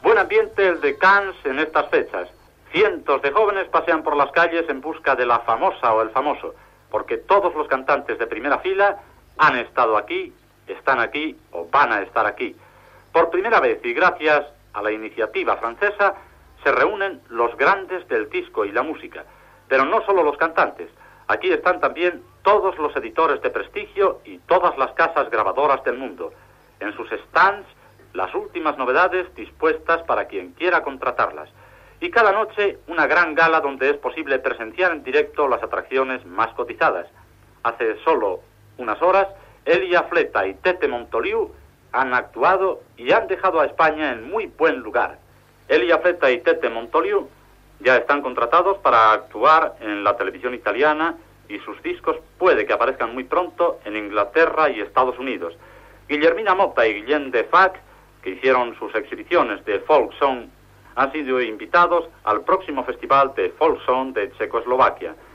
Radio Nacional de España Barcelona
Fragment extret del programa "Audios para recordar" de Radio 5 emès el 31 d'octubre del 2016.